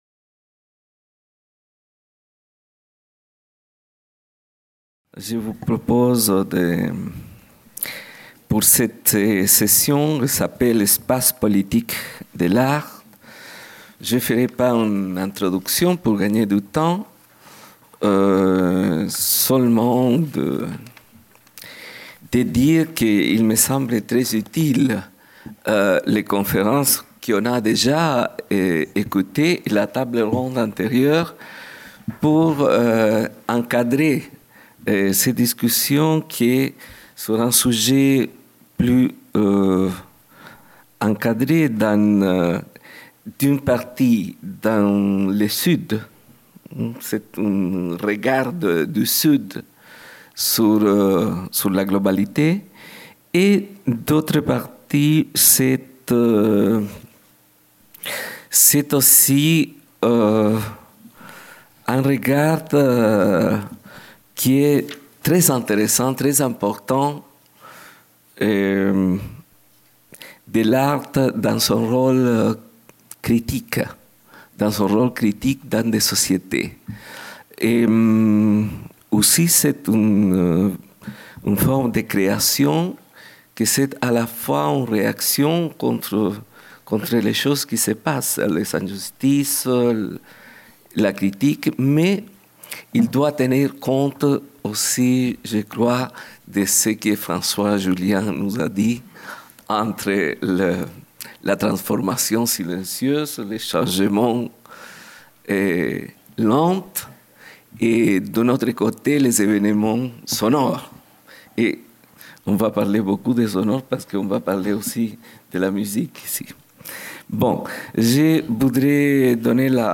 Jeudi 19 mai - Philharmonie, salle de conférence 11h15 Espaces politiques de l’art : « re-créer le monde » : une vue du Sud global par Françoise VERGES